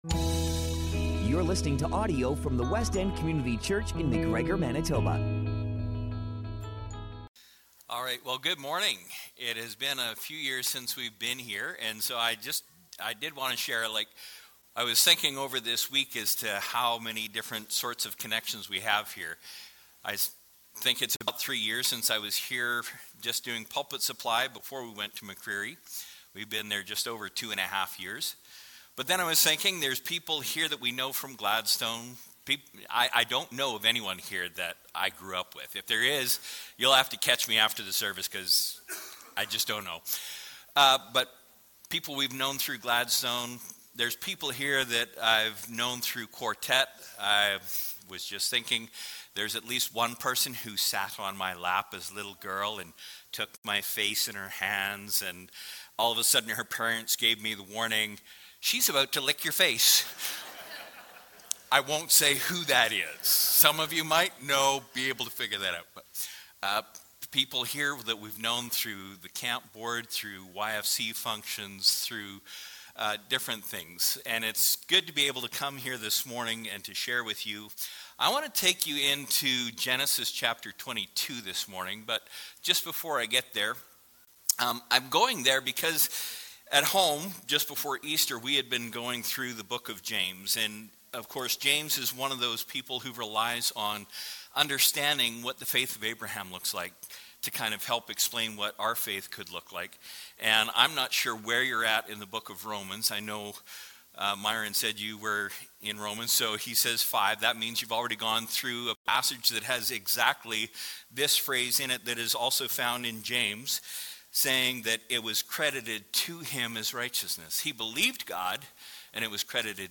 Sunday Sermon April 12th